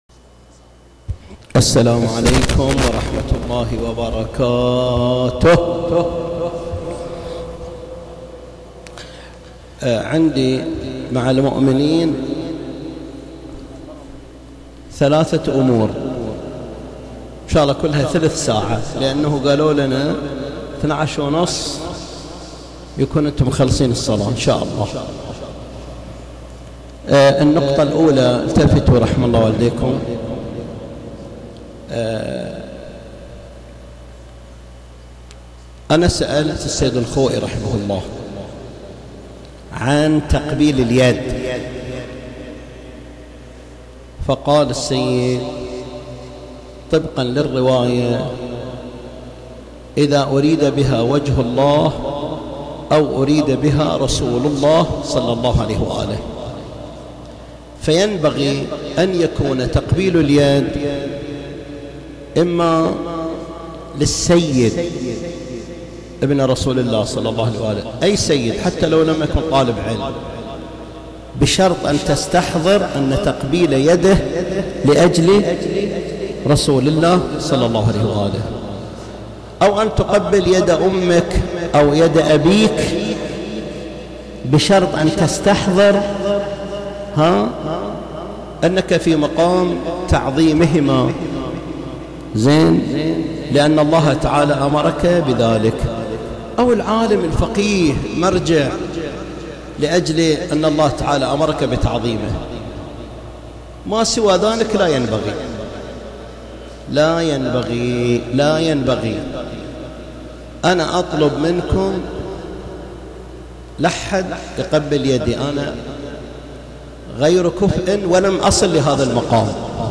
خطبة الجمعة – حول شهر رمضان